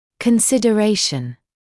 [kənˌsɪdə’reɪʃn][кэнˌсидэ’рэйшн]рассмотрение, обсуждение; соображение